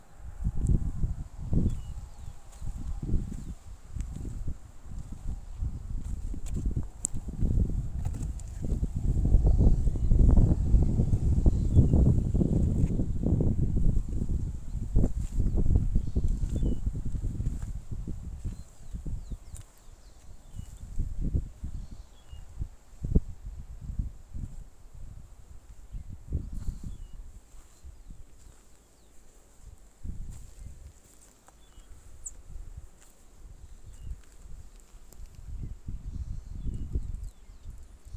Mourning Sierra Finch (Rhopospina fruticeti)
Life Stage: Adult
Condition: Wild
Certainty: Observed, Recorded vocal